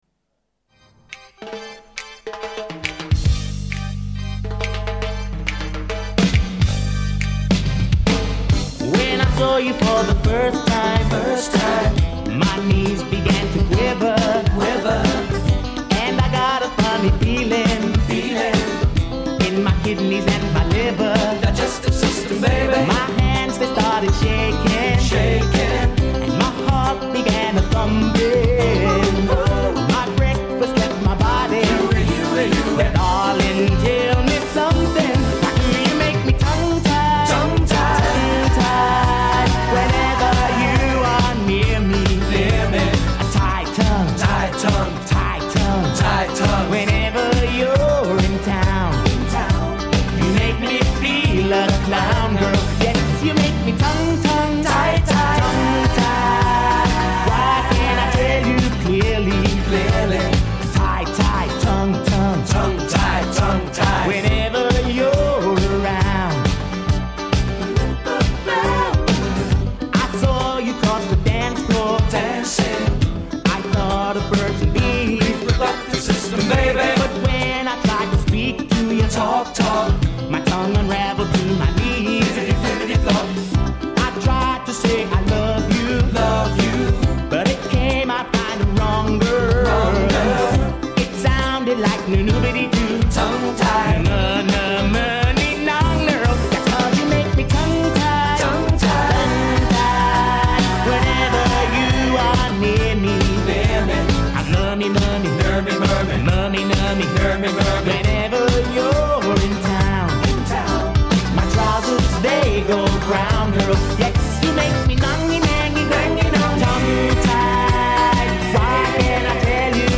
a pop love song with a catchy beat and some amusing lyrics.